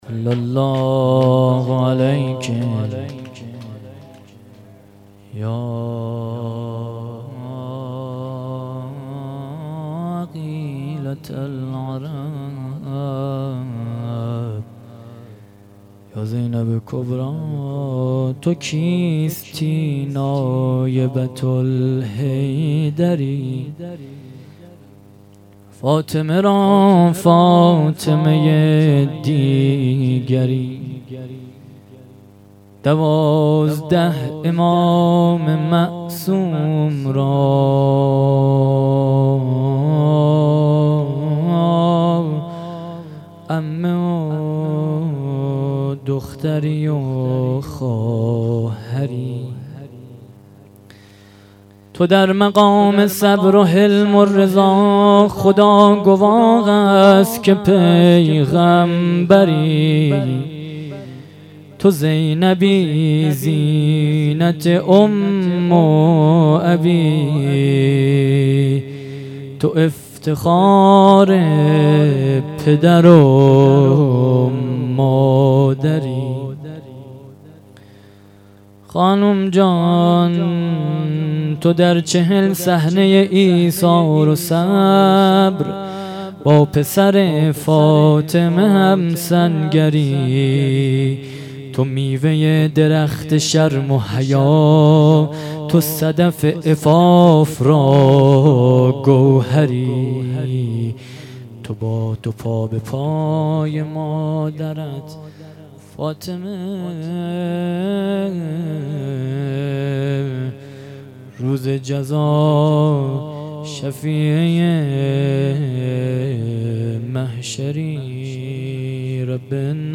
:: گزارش صوتی برنامه شهادت امام صادق علیه السلام 1437 هـ.ق - 1395 هـ.ش ::
روضـه پـایـانی